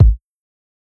KICK_GRITS_N_BACON.wav